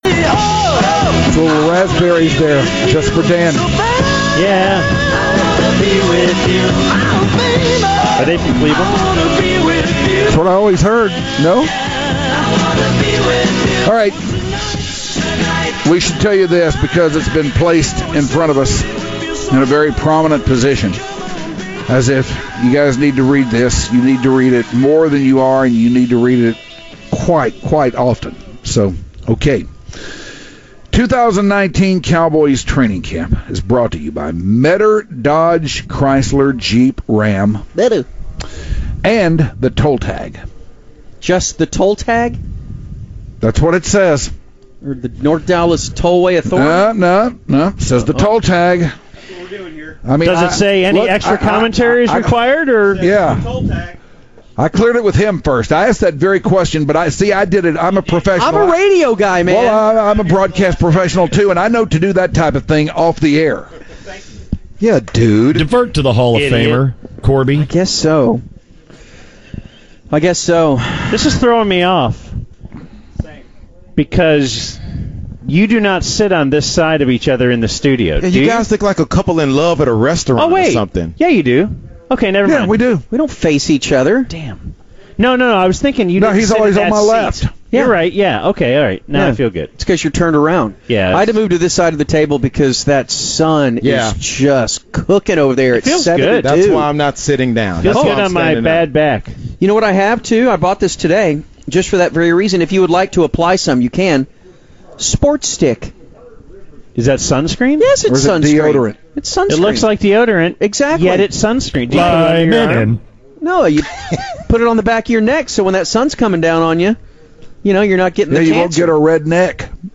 Training Camp Round Table - A.I. Talk - 7.31.19 - The UnTicket